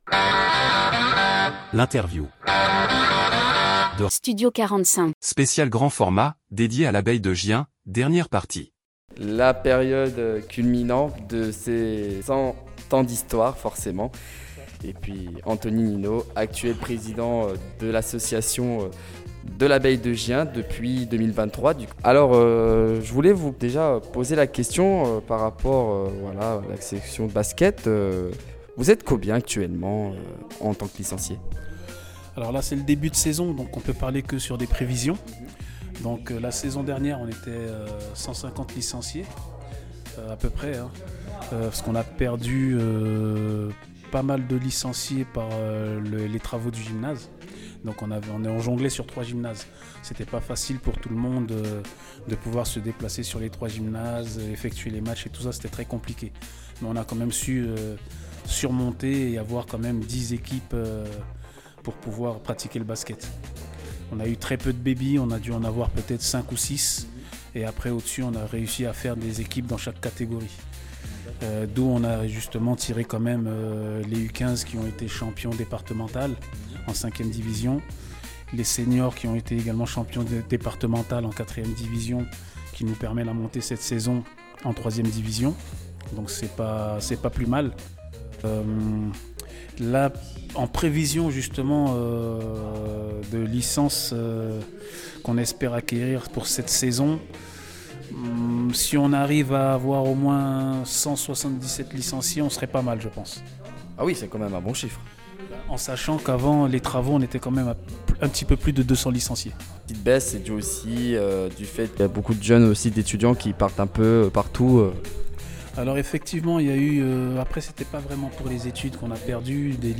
Interview Studio 45 - Les 100 ans de l’Abeille de Gien – Partie 3